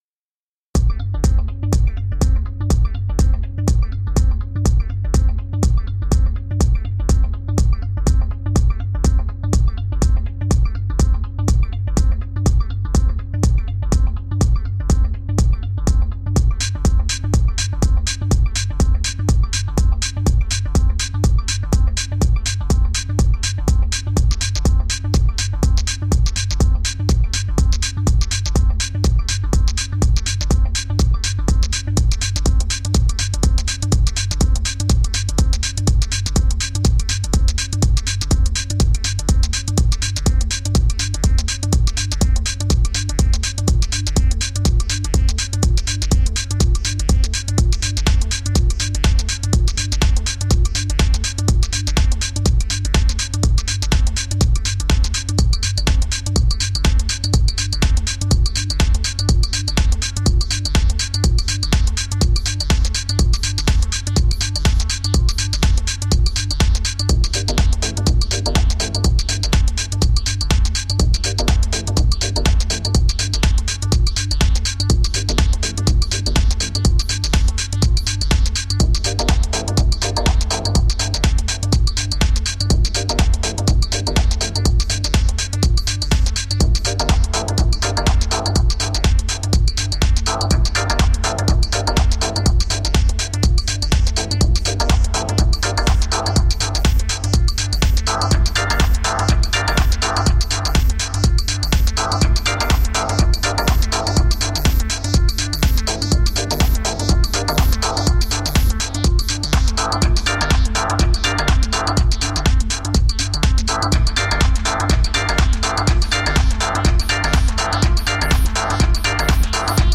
Minimal techno..
Tagged as: Electronica, Techno, Hard Electronic